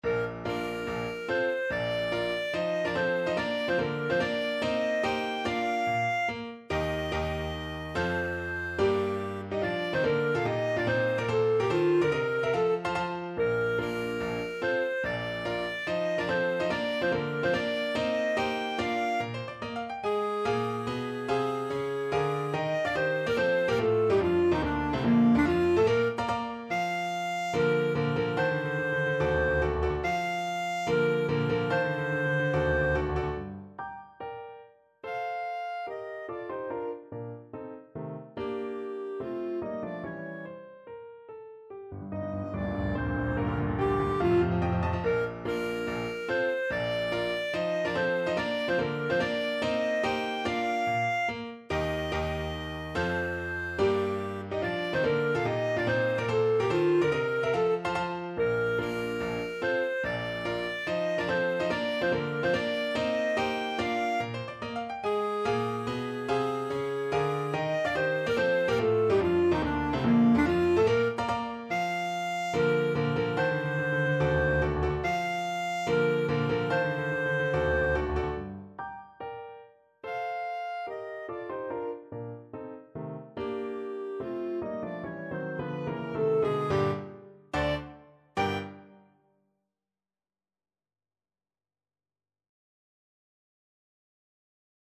Clarinet
4/4 (View more 4/4 Music)
Bb major (Sounding Pitch) C major (Clarinet in Bb) (View more Bb major Music for Clarinet )
Allegro non troppo (=72) (View more music marked Allegro)
Classical (View more Classical Clarinet Music)